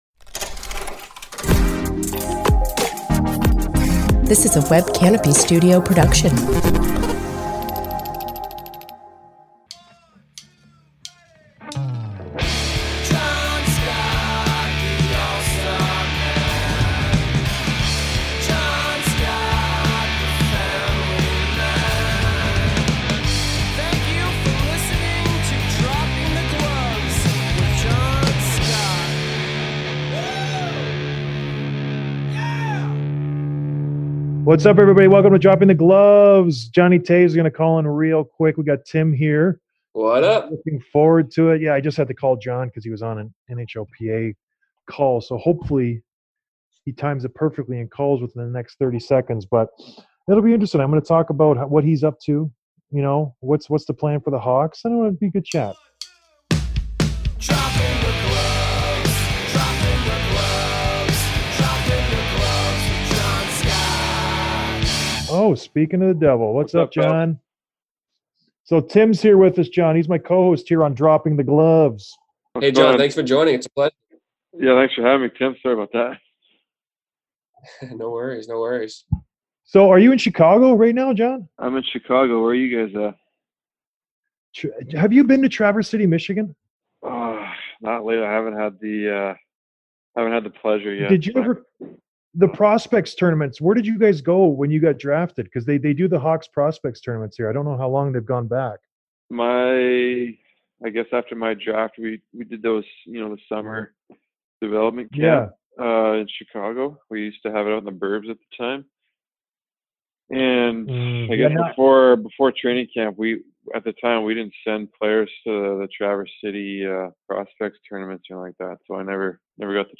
Interview with Blackhawks Captain, Jonathan Toews